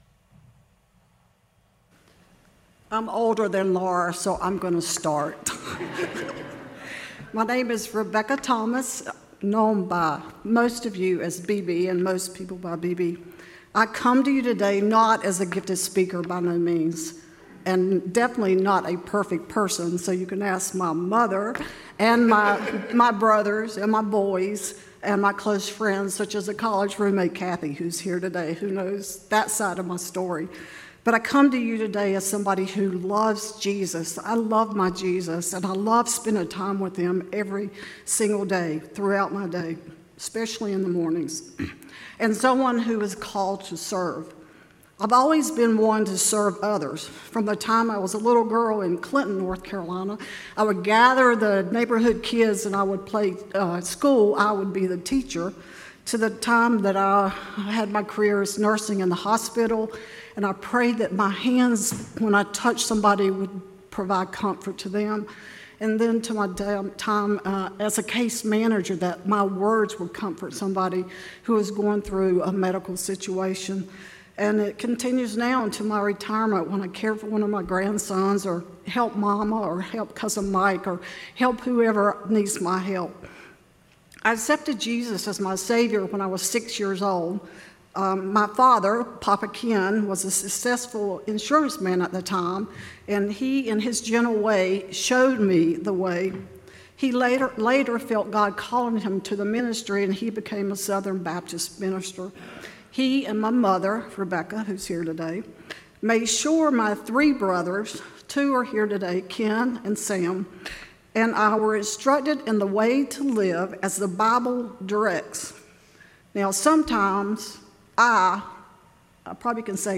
Deacon Ordination Testimonies
Romans Service Type: Testimony God’s call often rises through story.